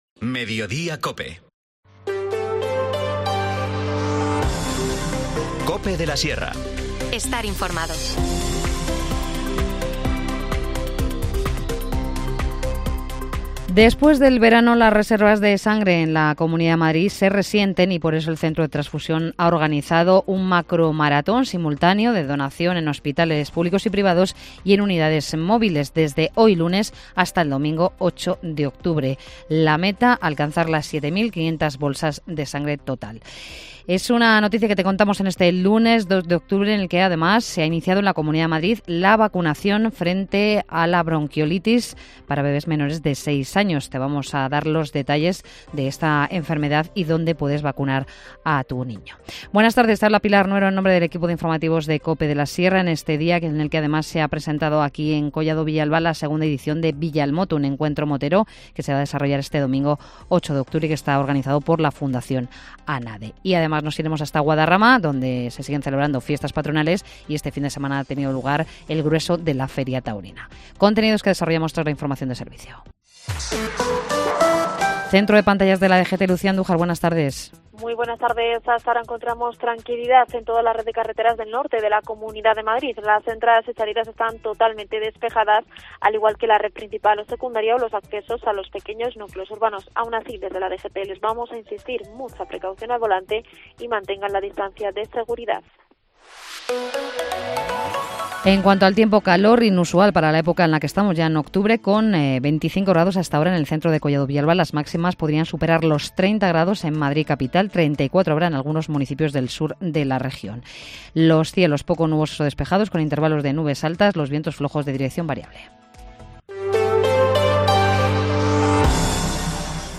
Informativo | Mediodía en COPE de la Sierra, 2 de octubre de 2023